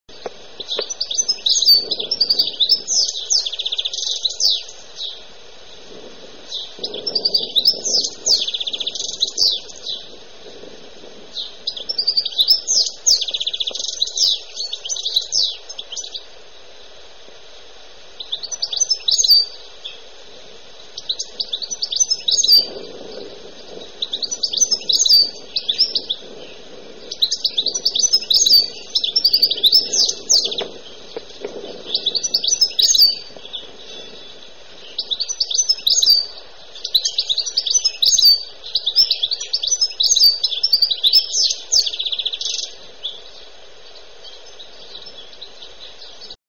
Pintassilgo
Carduelis carduelis
Pintassilgo.mp3